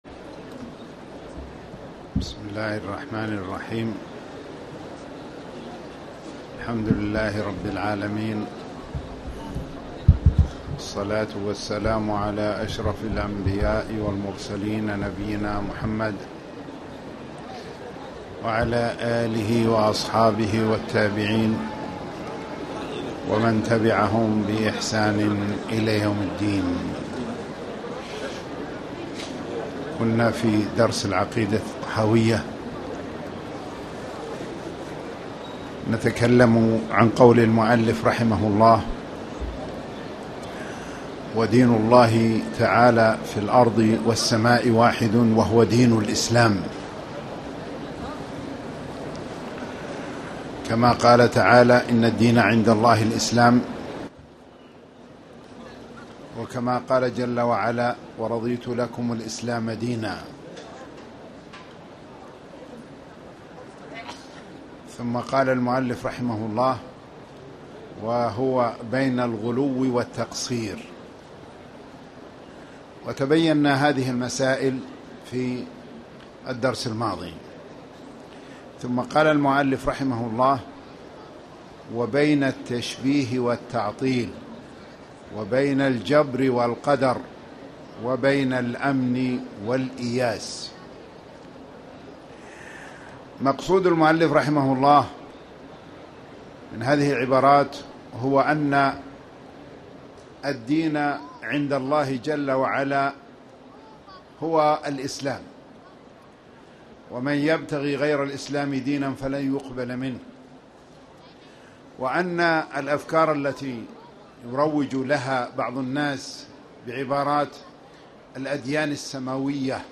تاريخ النشر ٥ شعبان ١٤٣٨ هـ المكان: المسجد الحرام الشيخ